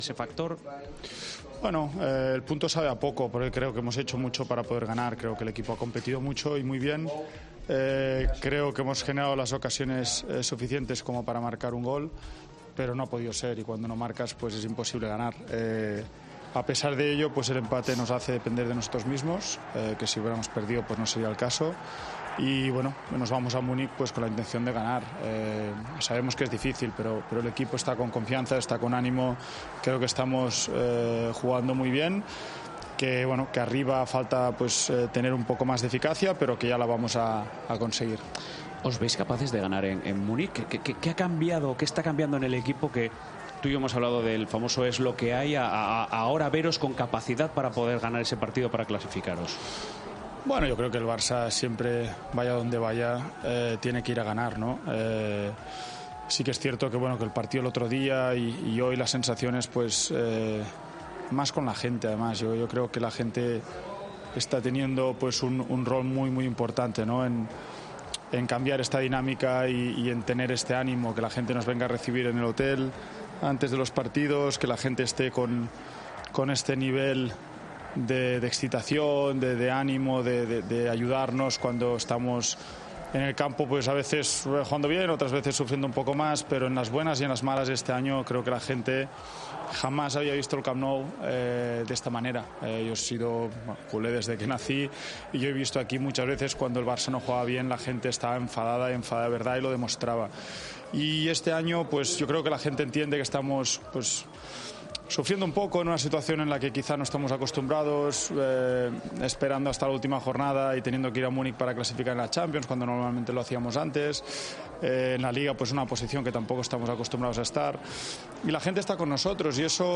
AUDIO: El central del Barcelona habló en Movistar del empate ante el Benfica, la obligación de ganar en Munich y el cambio del equipo con Xavi.